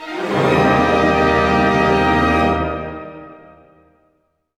Index of /90_sSampleCDs/Roland LCDP08 Symphony Orchestra/HIT_Major Hits/ORC_Major Gliss